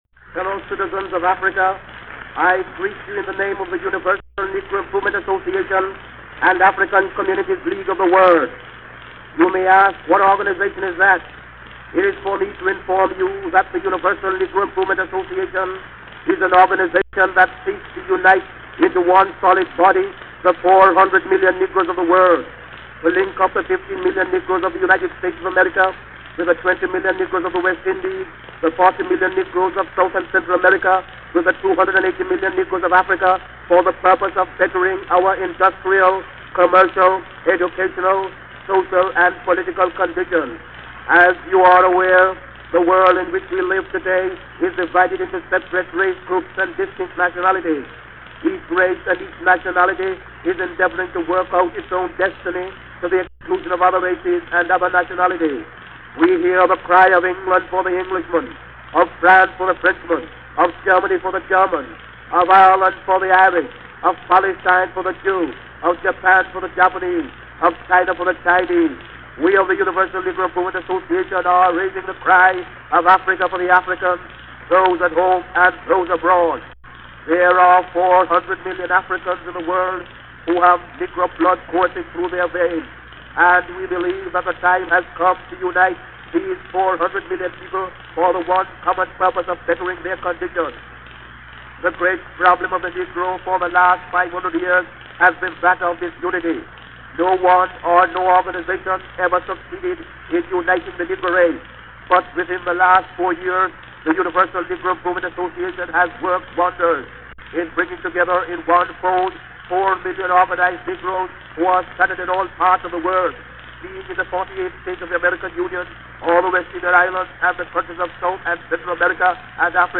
Kicking off Black History Month this year with two short addresses by Marcus Garvey.
Here are two excerpts from addresses he made – sadly, the only known recordings of his voice known to exist, recorded in 1921.